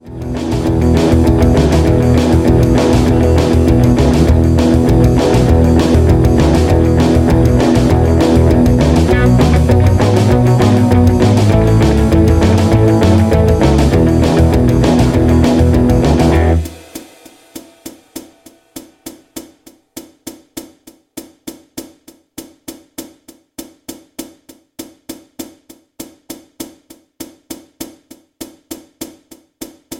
MPEG 1 Layer 3 (Stereo)
Backing track Karaoke
Rock, Oldies, 1960s